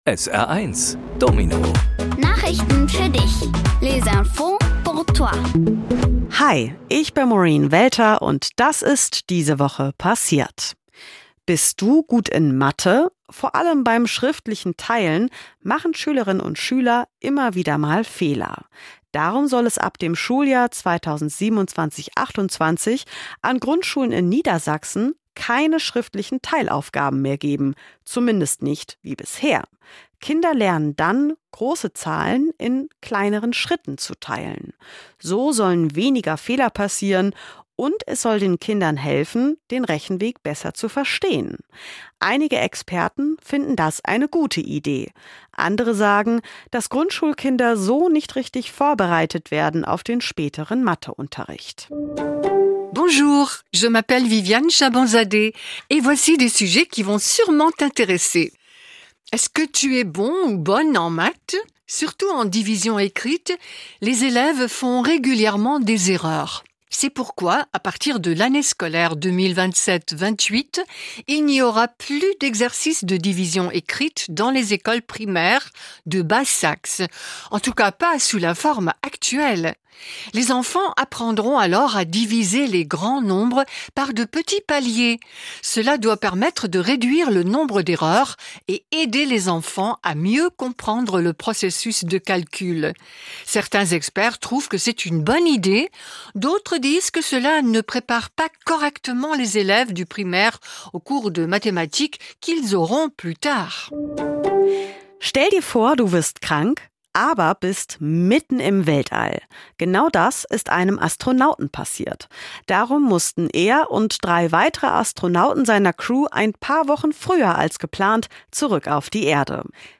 Kindgerechte Nachrichten auf Deutsch und Französisch:-